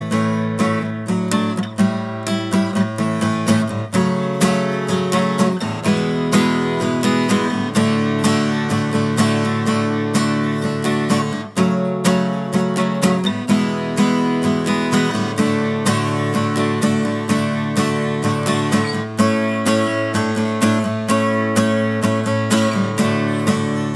Rhythm Guitar Only Mix Rock 3:46 Buy £1.50